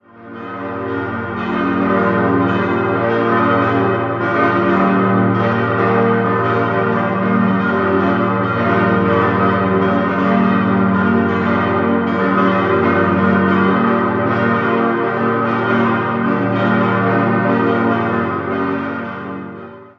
Nach Auflösung der paritätischen Nutzung wurde die Kirche von der katholischen Gemeinde weiter genutzt und 1938/39 umgestaltet und erweitert. 6-stimmiges Geläut: g°-b°-d'-f'-g'-b' Die Glocken goss im Jahr 1937 die Firma Rüetschi in Aarau. Nach jahrzehntelangem Schweigen konnte die große Glocke 2012 geschweißt werden.